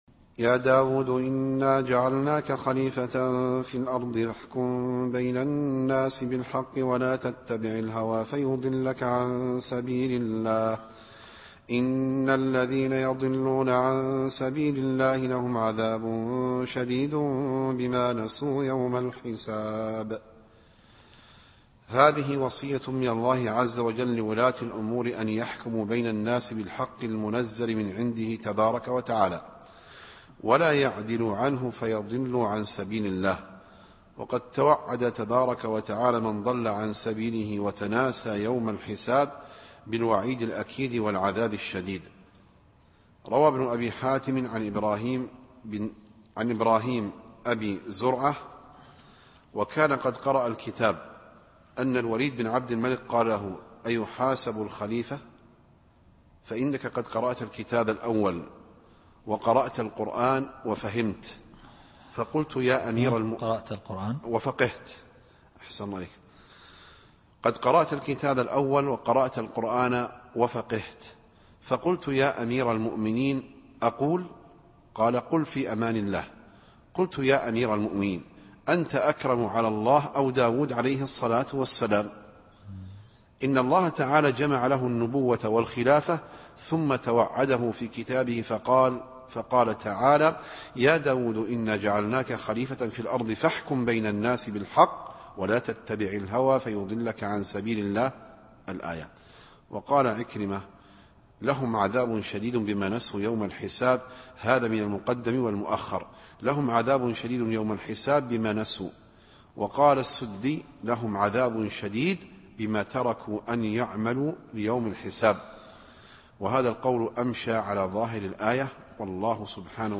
التفسير الصوتي [ص / 26]